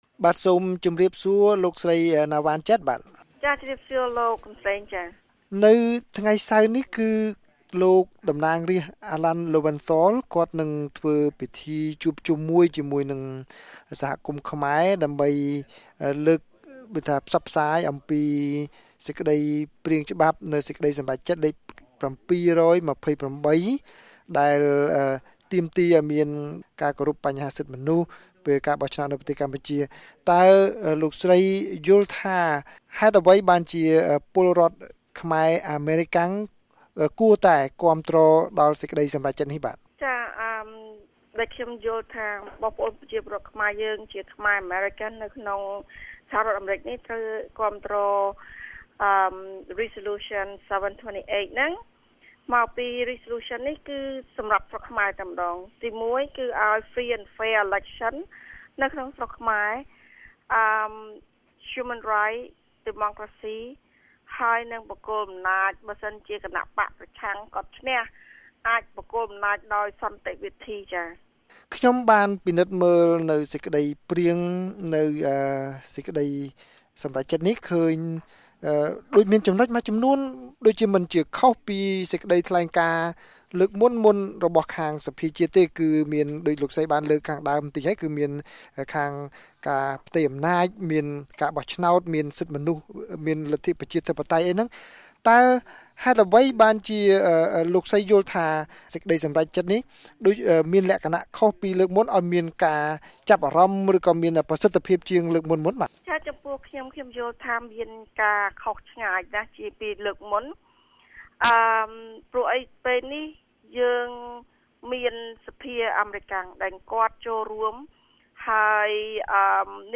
បទសម្ភាសន៍ VOA៖ សមាជិកសភាអាមេរិកពន្យល់សហគមន៍ខ្មែរពីសារៈសំខាន់នៃសេចក្តីសម្រេចចិត្តលេខ៧២៨